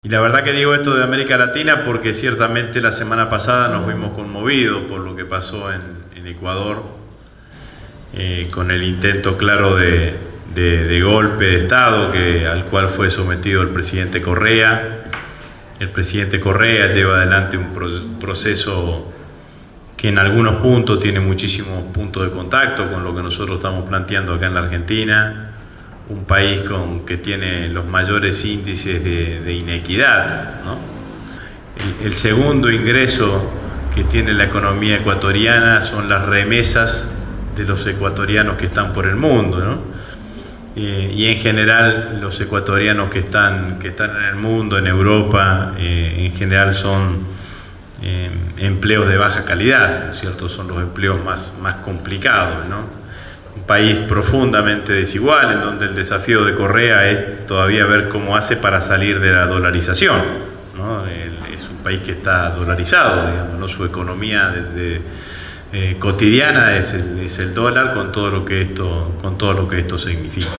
El último 4 de Octubre, organizado por la agrupación «Todas con Cristina«, estuvo presente en el espacio de la Radio Gráfica Agustín Rossi, Presidente del Bloque del FPV en la cámara baja.
Casi dos horas de charla-debate sirvieron para dejar satisfecha a la concurrencia, responder inquietudes y plantear nuevos desafios